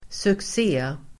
Uttal: [suks'e:]